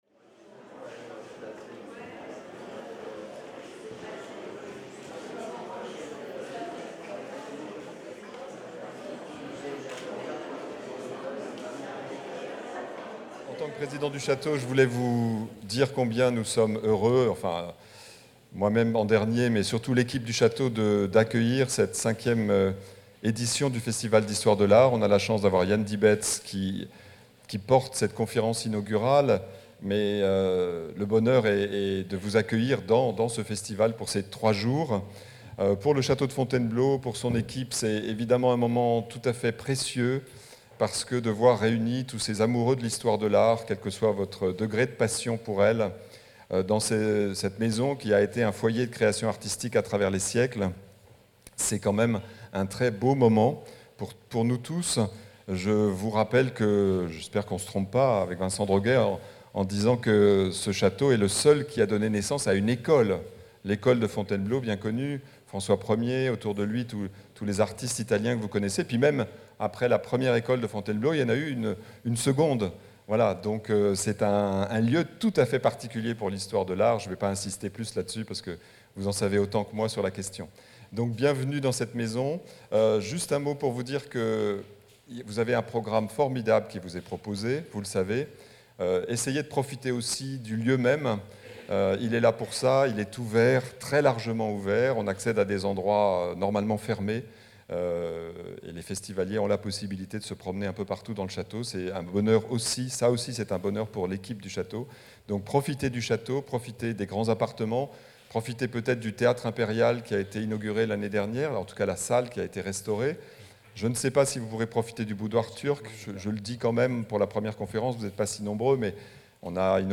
Entre concept et image dans l’oeuvre de Jan Dibbets - conférence inaugurale de la 5e édition du Festival | Canal U